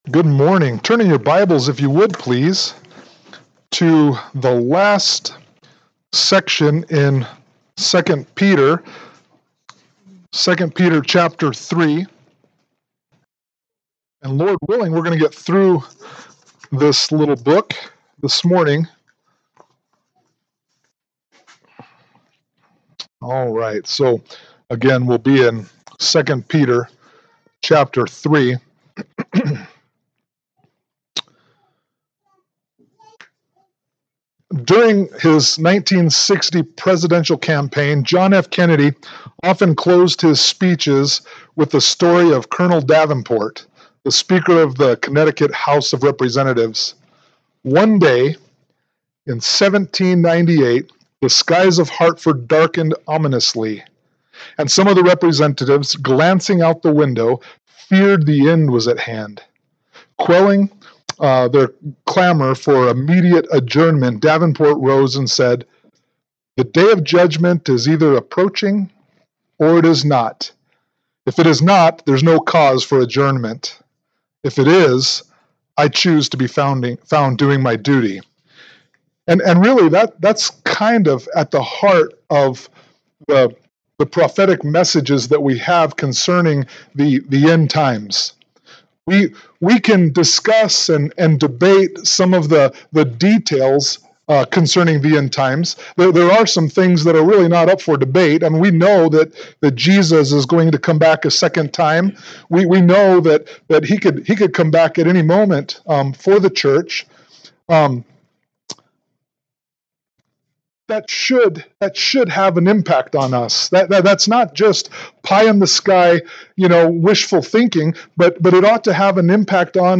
2 Peter 3:10-18 Service Type: Sunday Morning Worship « 2 Peter 3:1-9 John 1:1-18